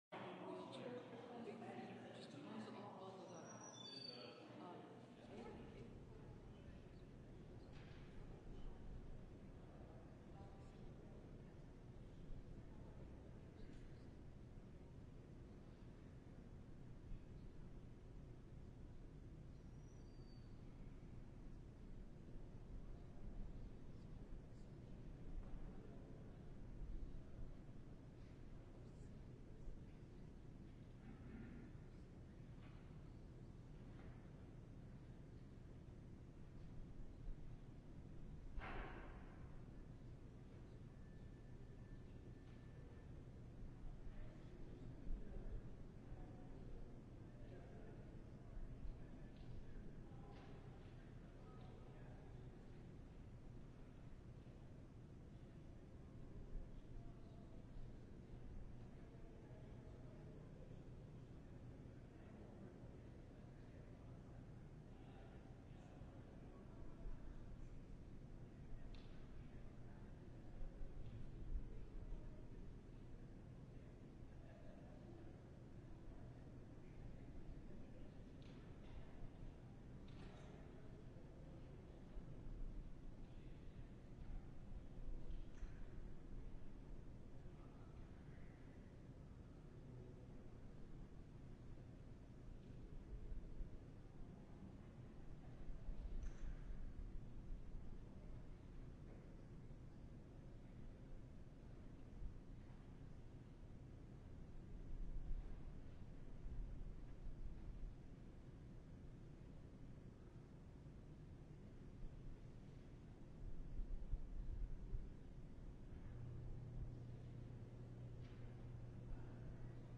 LIVE Evening Worship Service - Forgiven and Blessed